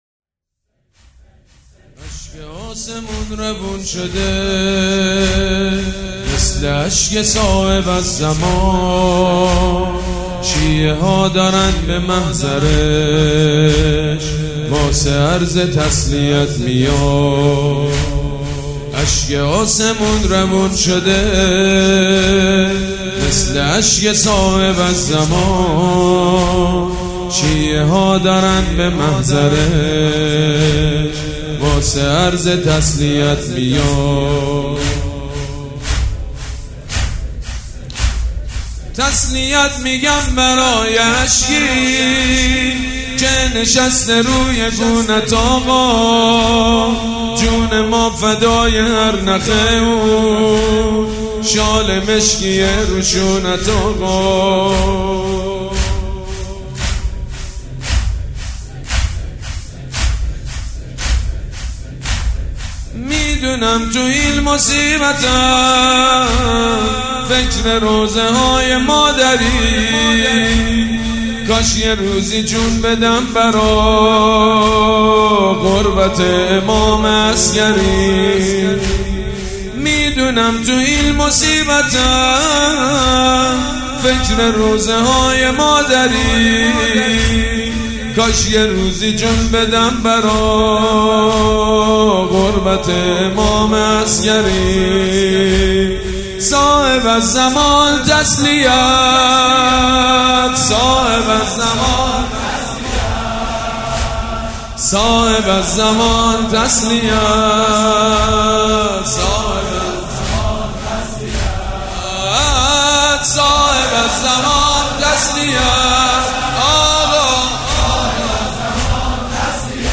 مداحی به مناسببت سالروز شهادت امام حسن عسکری (ع) با نوای بنی فاطمه در ادامه قابل بهره برداری است.